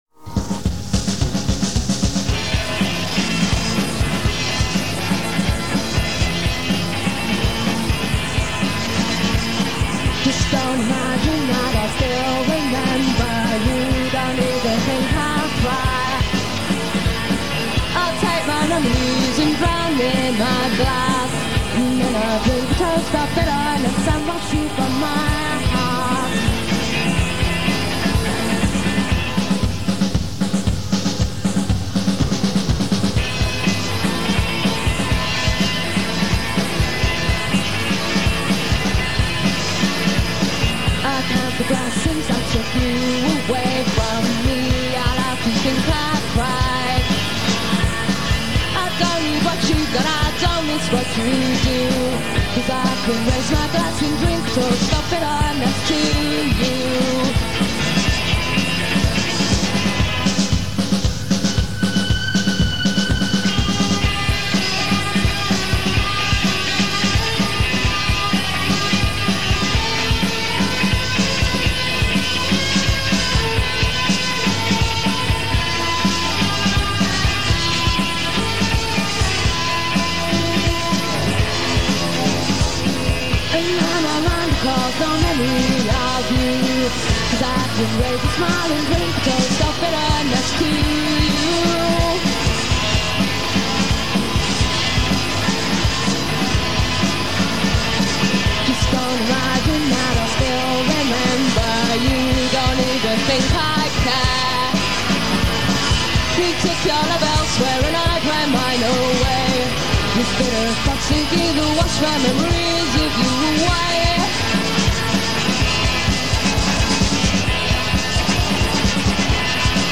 recorded live
The 90s Era of Infinite shoegaze.
the dream-like quality that stuck in your head forever.